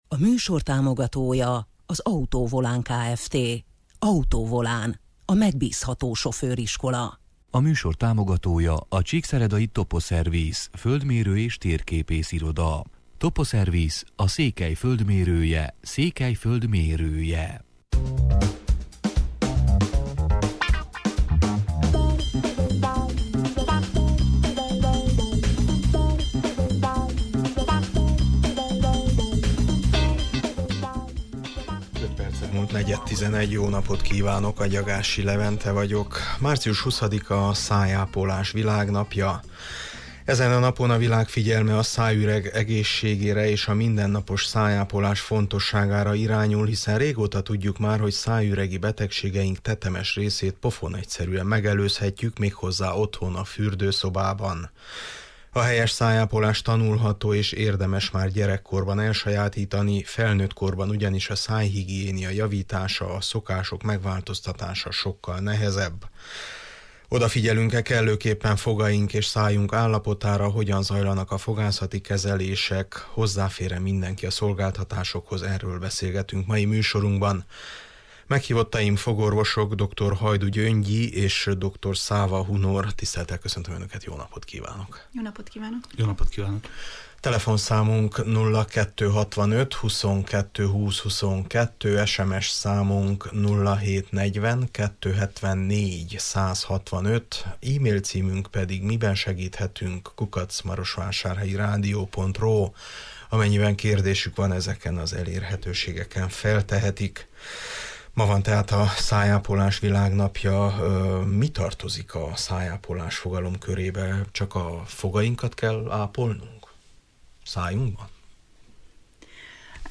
Odafigyelünk-e kellőképpen fogaink és szájunk állapotára, hogyan zajlanak a fogászati kezelések, hozzáfér-e mindenki a szolgáltatásokhoz – erről beszélgetünk mai műsorunkban.